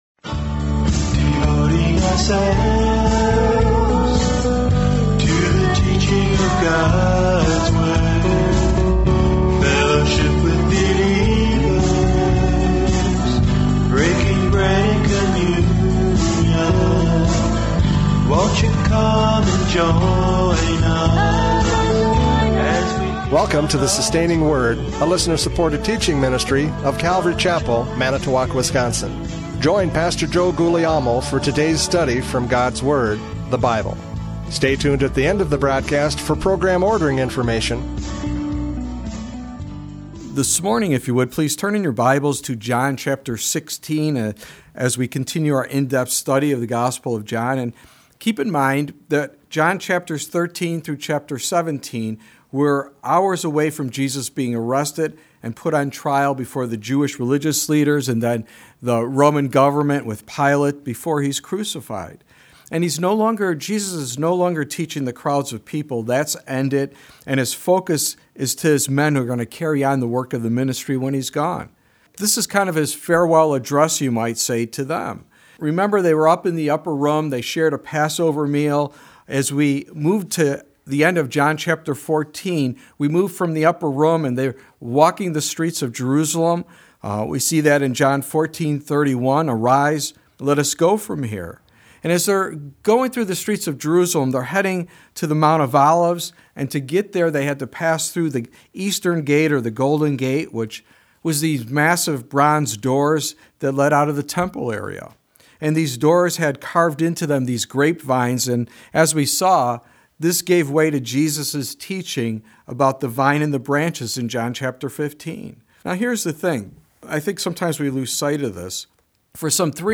John 16:1-7 Service Type: Radio Programs « John 15:18-27 The World’s Response!